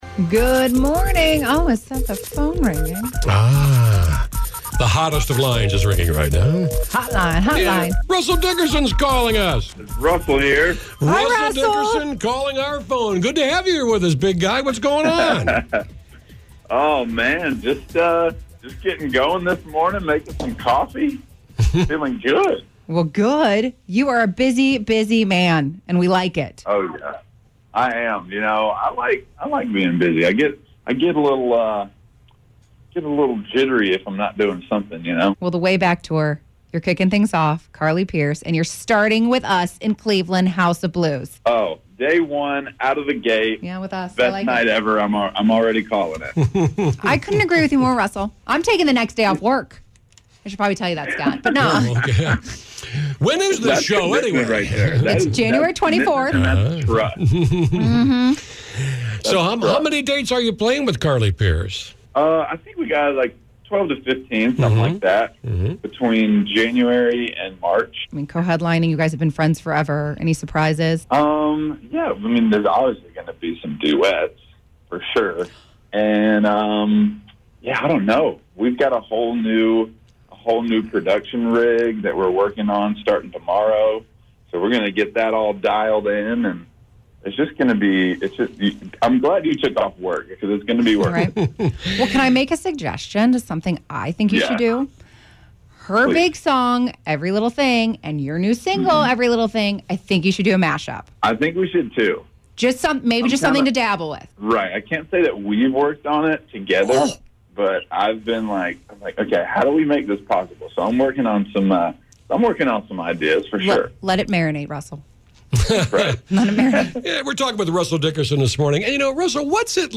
Russell Dickerson called into the show to chat about his new The Way Back tour with Carly Pearce, his music and more!
Russell-Dickerson-Interview-11619.mp3